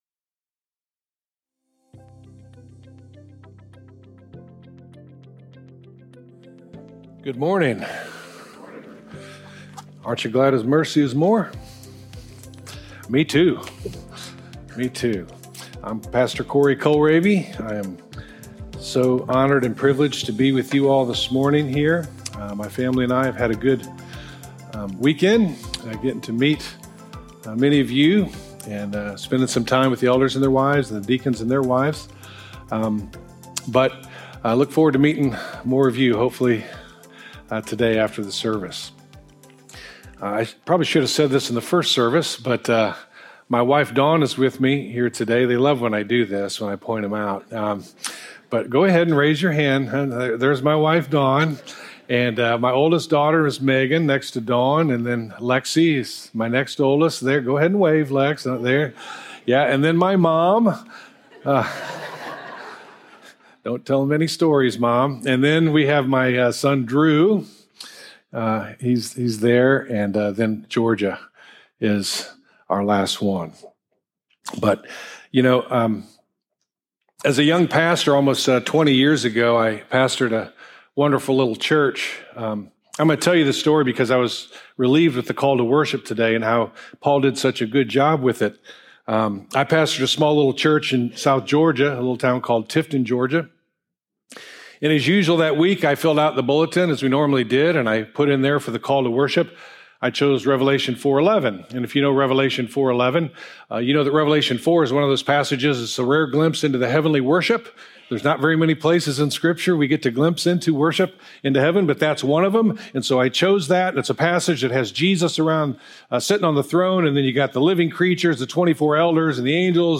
Our Candidate for Senior Pastor continues thru our Witnesses In A Watching World message series, by calling our attention to 1 Corinthians 7. Through the tough but timeless themes of love and contentment, and the sacredness of marriage – we get a glimpse into sexual holiness being often misconstrued in today's culture, where the physical is celebrated over the spiritual, and the quick fix is preferred over the lasting covenant of a God-designed marriage.